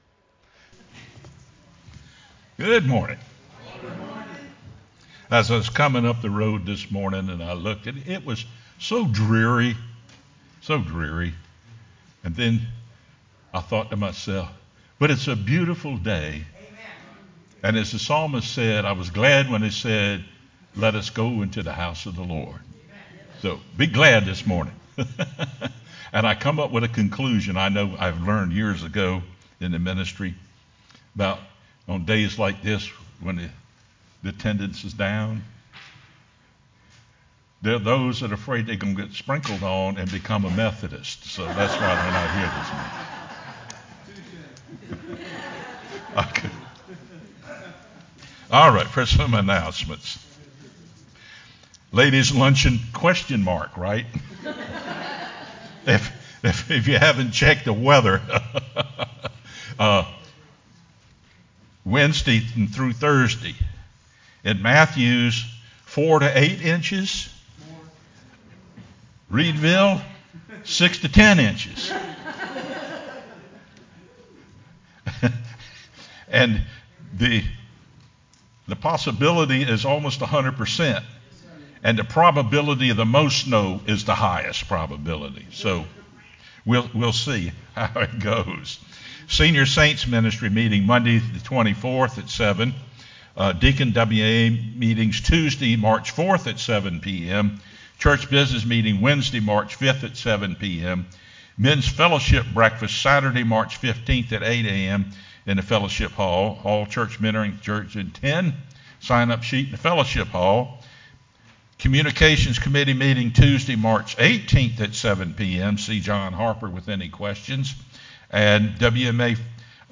sermonFeb16-CD.mp3